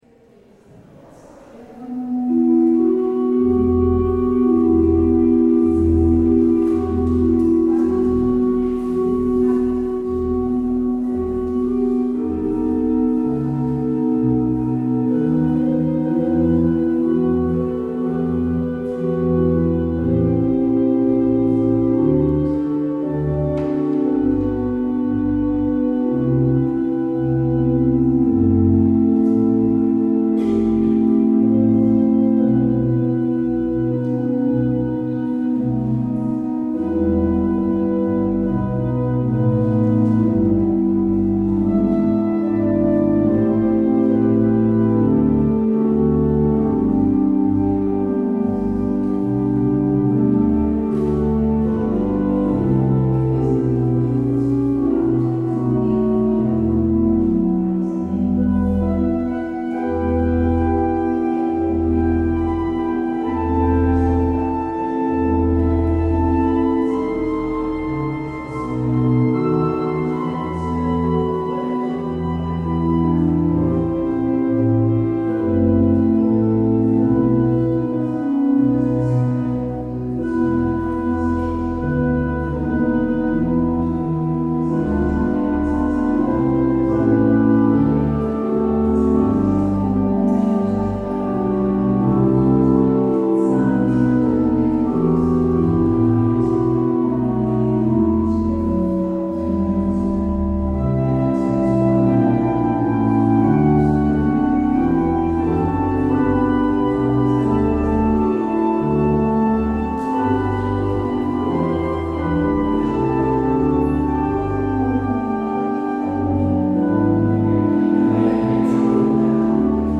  Luister deze kerkdienst hier terug
Het openingslied is Geroepen om te zingen 18: 1 en 2 (melodie lied 675).